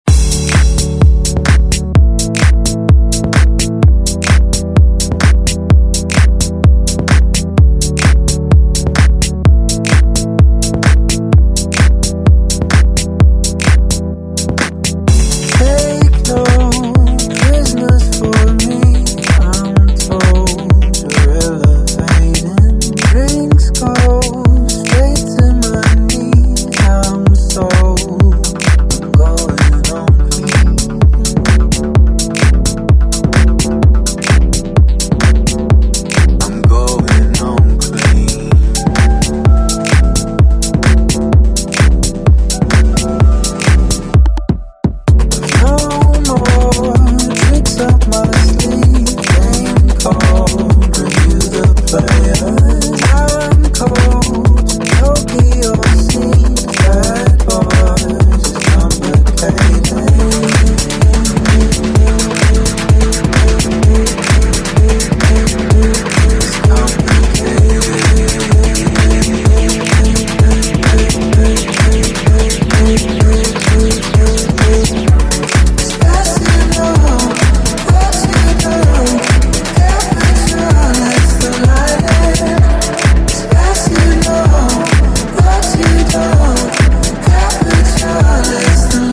gtimh Genre: RE-DRUM Version: Clean BPM: 128 Time